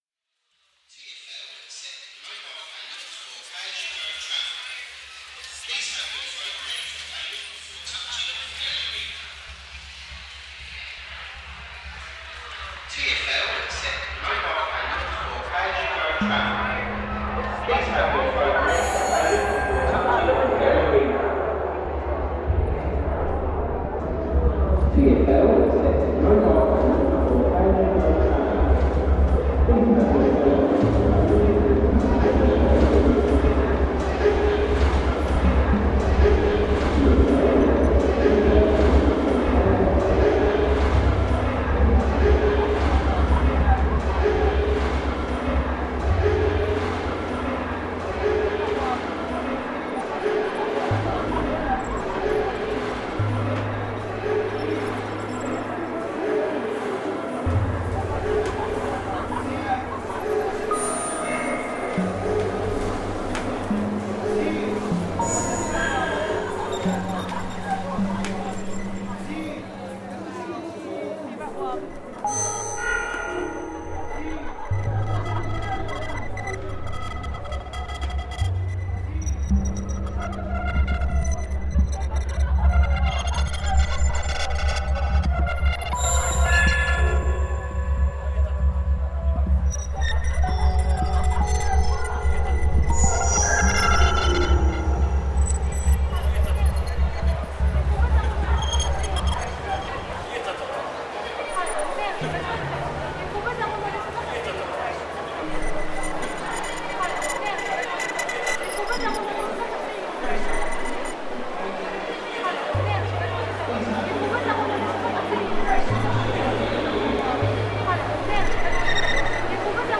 Part of our project The Next Station, reimagining the sounds of the London Underground and creating the first ever tube sound map.